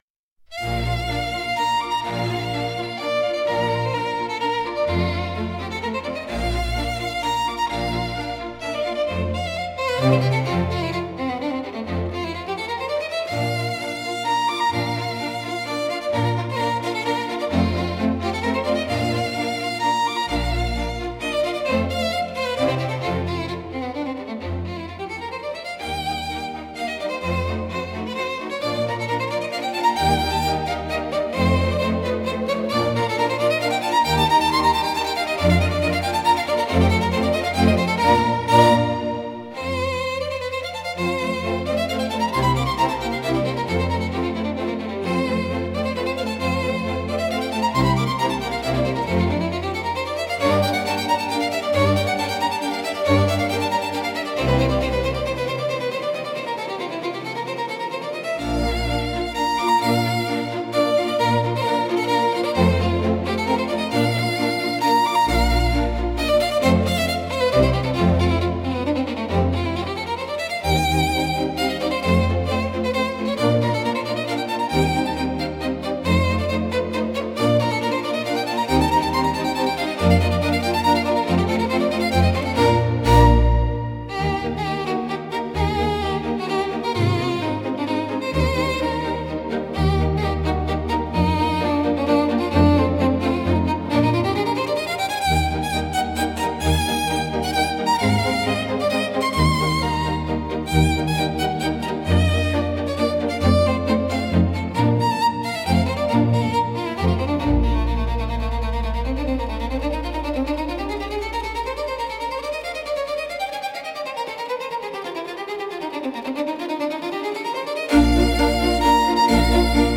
聴く人に高雅さや荘厳さ、心の深みを感じさせる普遍的なジャンルです。